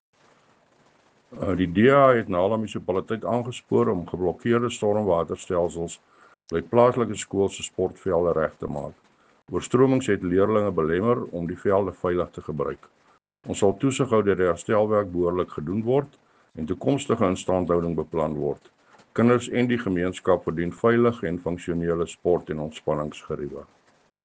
Afrikaans soundbites by Cllr Thinus Barnard and Sesotho soundbite by Cllr Kabelo Moreeng.